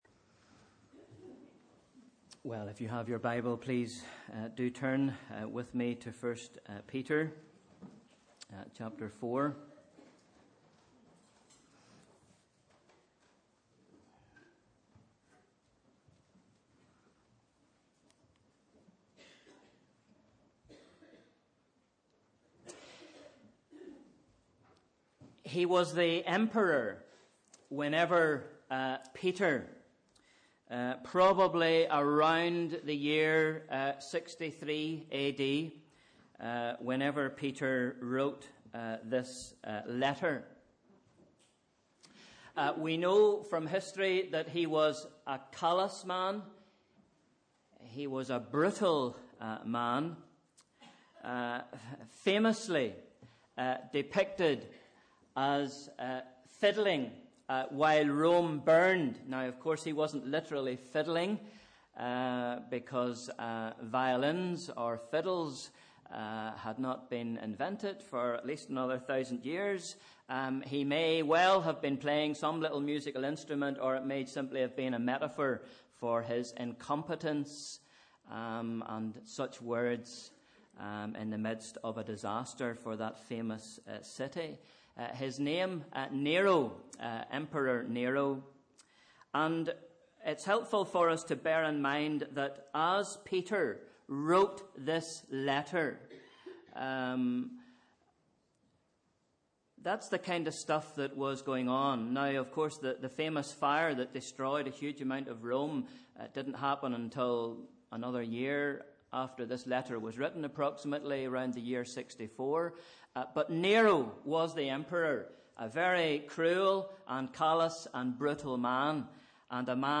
Sunday 19th July – Morning Service